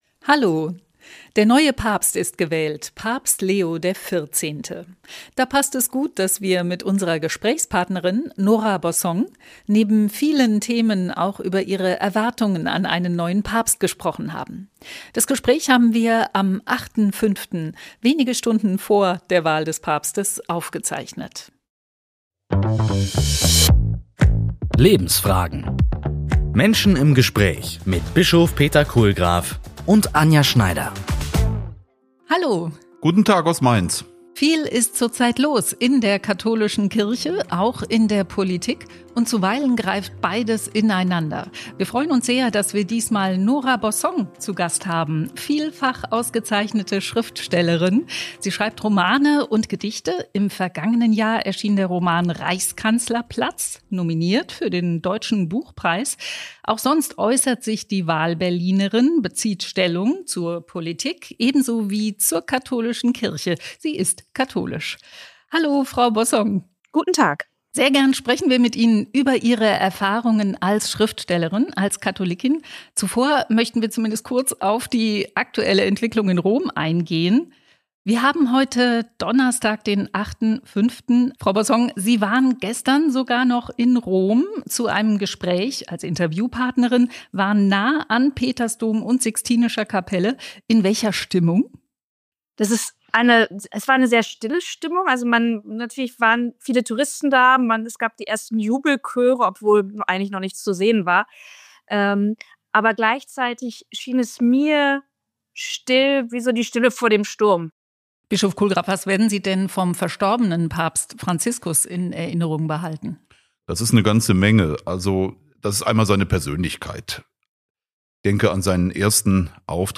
Lebensfragen - Menschen im Gespräch
Gast: Nora Bossong Thema: Über Päpste, Gott und die Literatur.